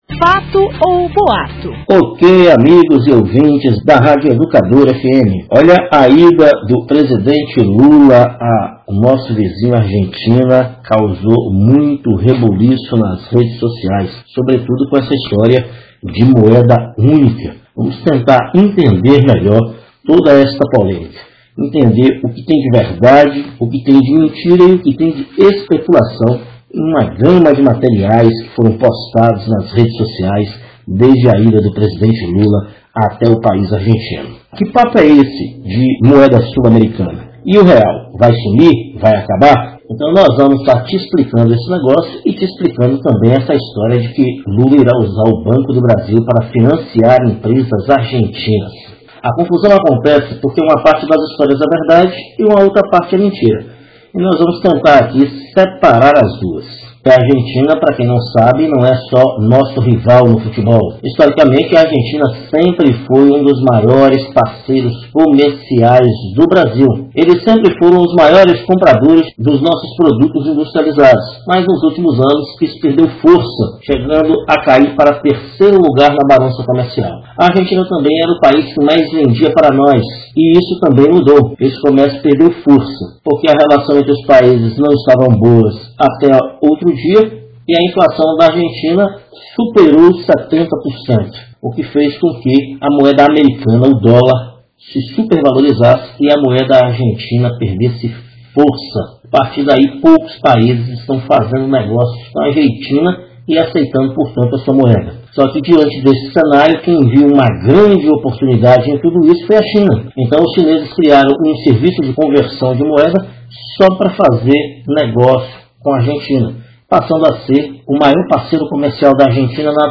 E a ida do presidente Lula a  Argentina causou uma enxurrada de informações nas redes sociais, nesta reportegem e possivel entendermos melhor do que se trata a “moeda comum” e também o possível financiamento do Brasil a empresas argentinas- play